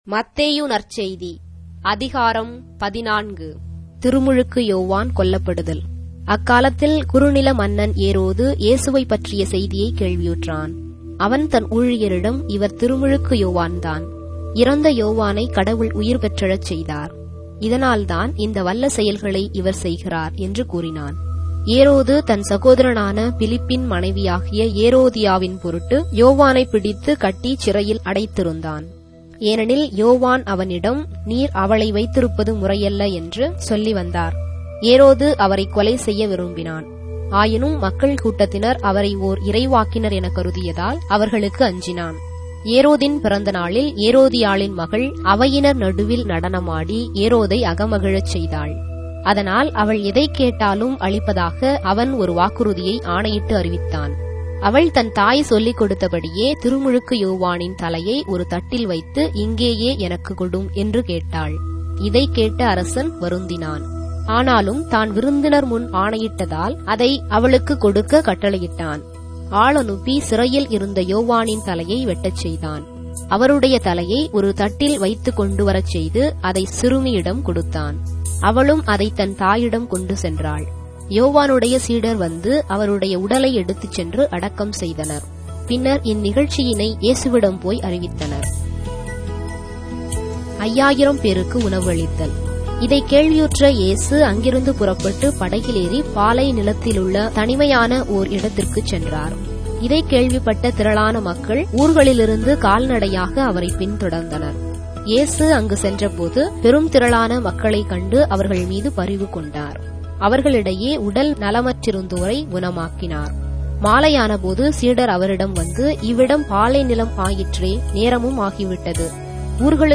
Tamil Audio Bible - Matthew 6 in Ecta bible version